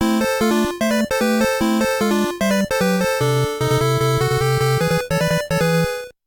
A jingle of unknown purpose